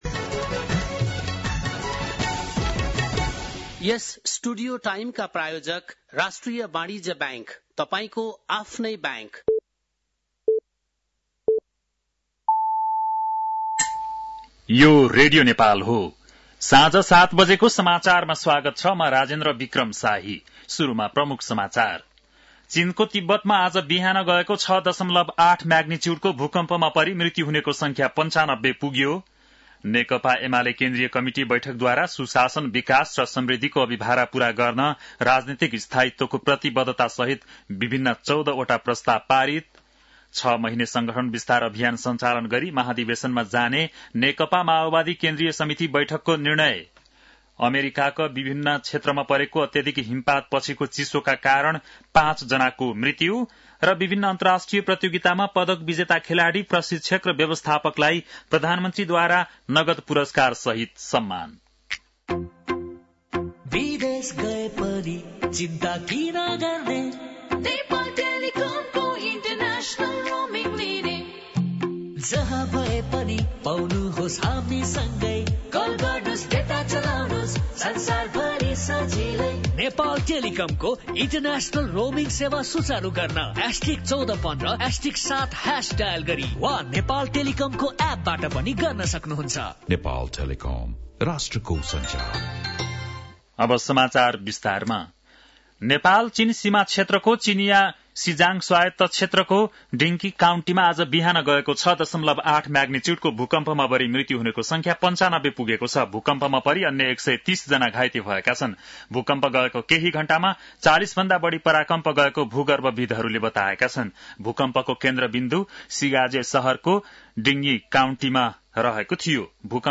बेलुकी ७ बजेको नेपाली समाचार : २४ पुष , २०८१
7-pm-nepali-news-9-23.mp3